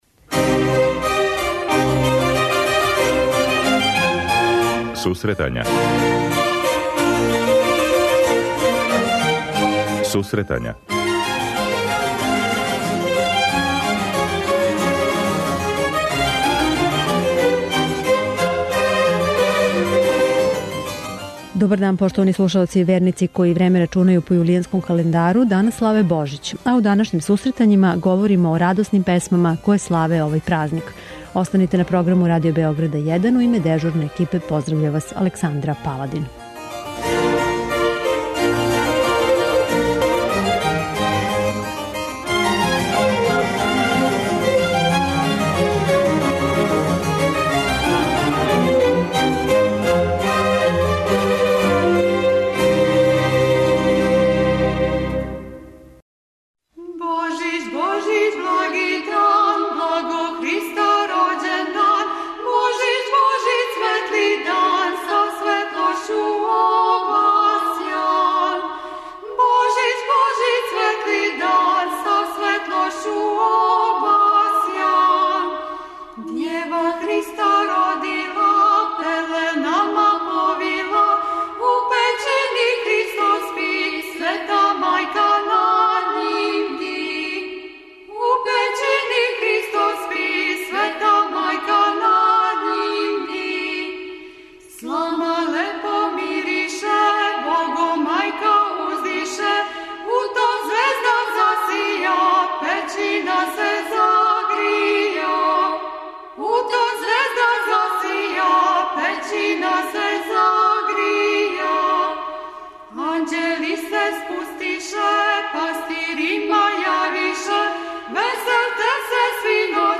преузми : 23.46 MB Сусретања Autor: Музичка редакција Емисија за оне који воле уметничку музику.